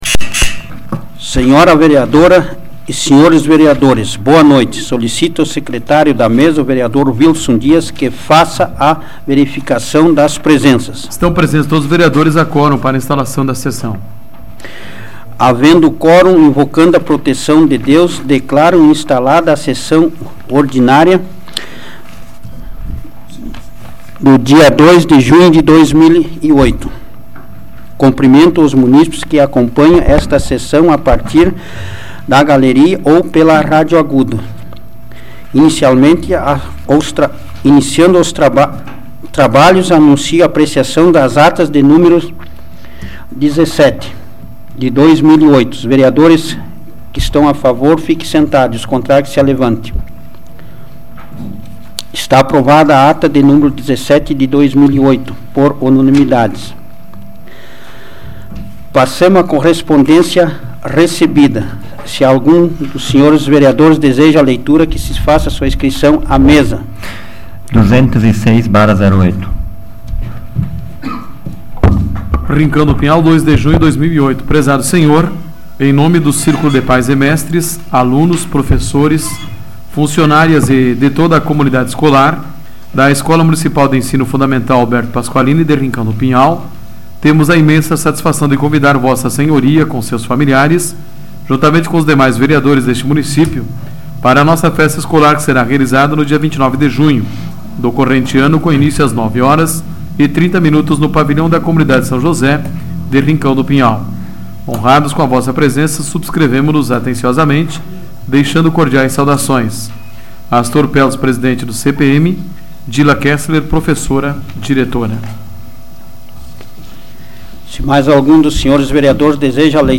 Áudio da 124ª Sessão Plenária Ordinária da 12ª Legislatura, de 02 de junho de 2008